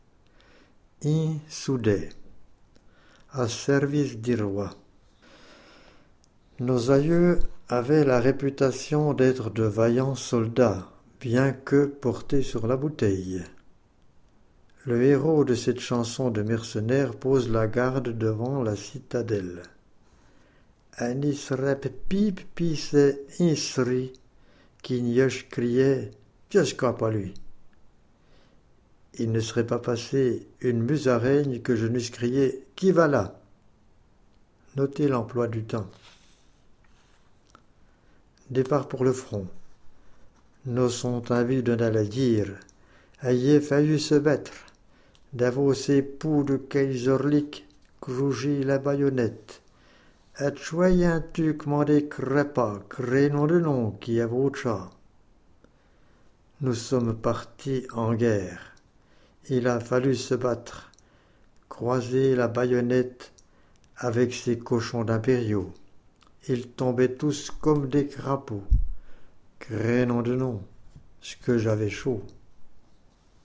Le héros de cette chanson de mercenaires pose la garde devant la citadelle.